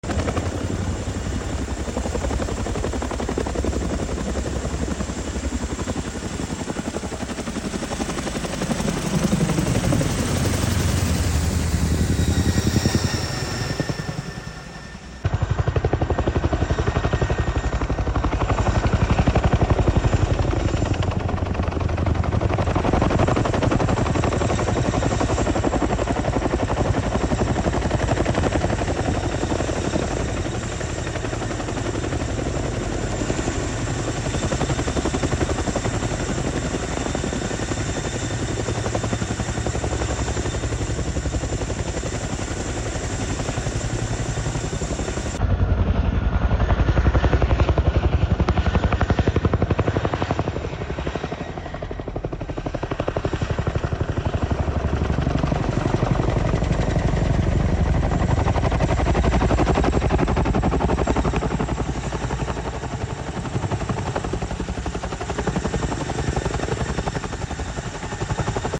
Royal Netherlands Air Force CH 47F sound effects free download
Royal Netherlands Air Force CH-47F during a trainings flight.